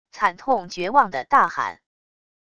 惨痛绝望地大喊wav音频